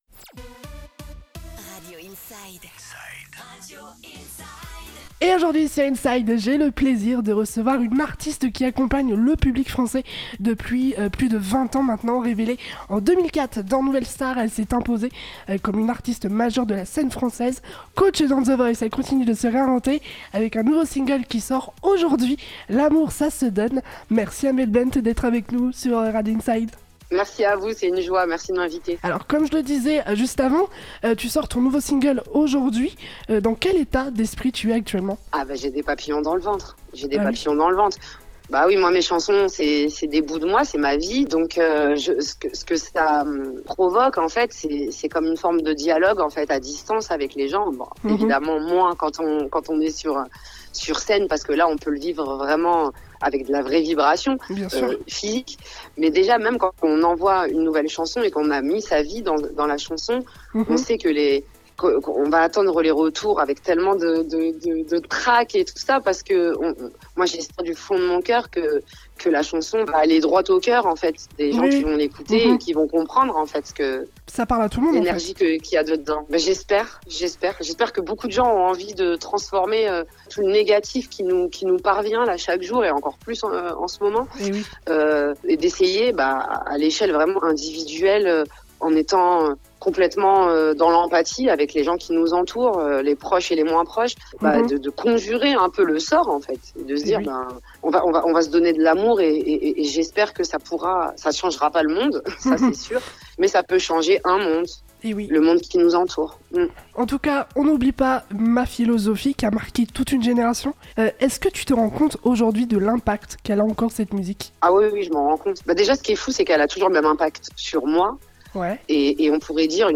Interview de Amel Bent en intégralité sur Radio Inside